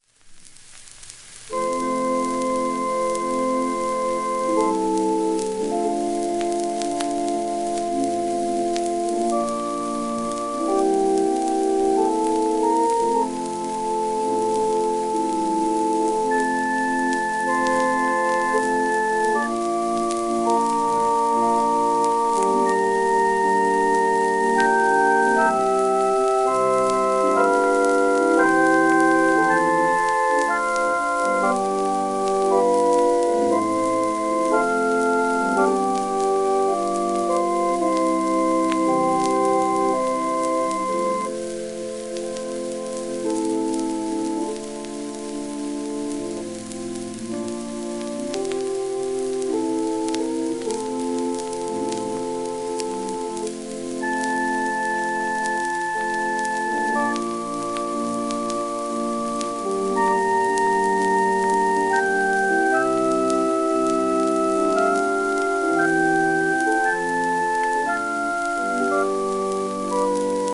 旧 旧吹込みの略、電気録音以前の機械式録音盤（ラッパ吹込み）